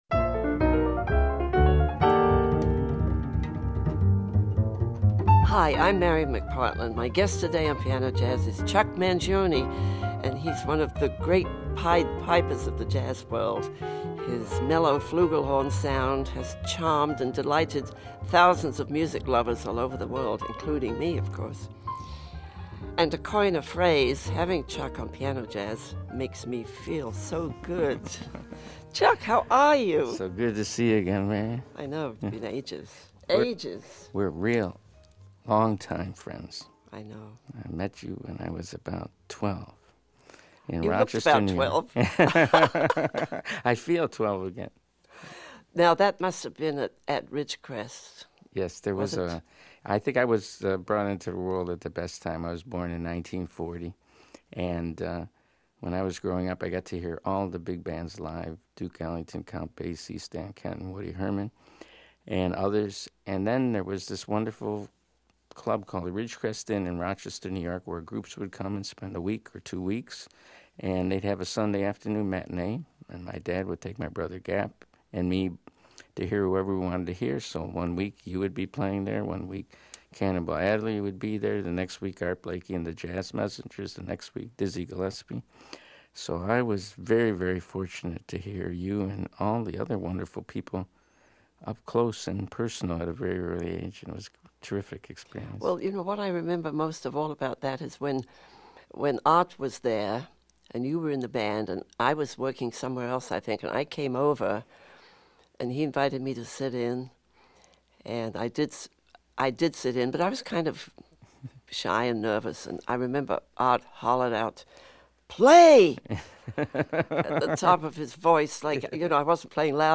dynamic trio work